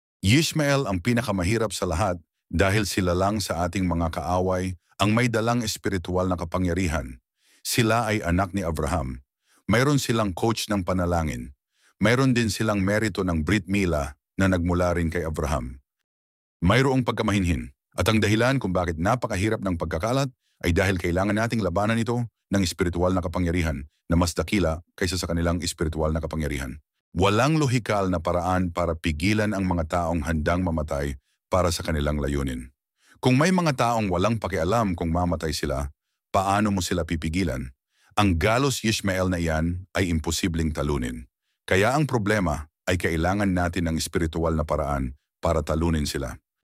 صوتية دوبلاج - شهادة حاخام يهودي عن المسلمين وإيمانهم